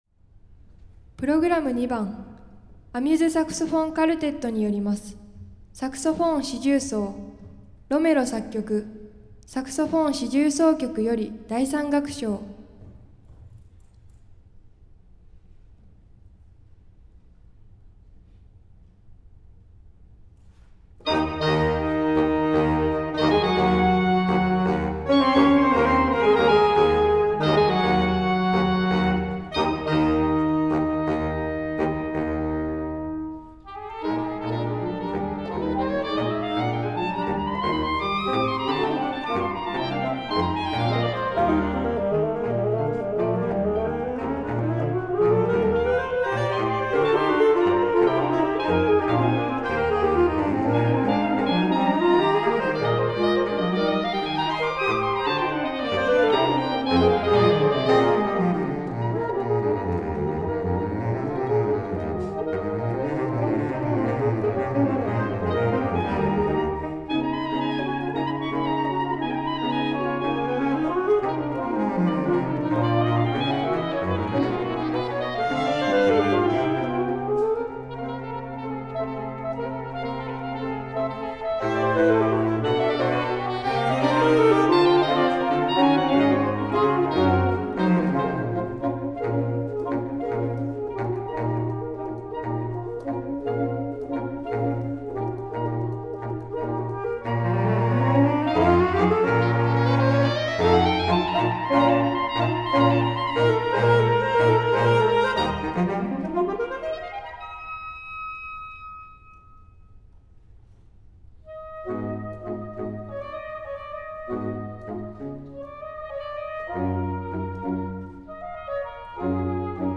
第52回大阪府大会アンサンブルコンテスト、無事に終了いたしました！
場　所：大東市立総合文化会館サーティホール（大ホール）
編　成：金管四重奏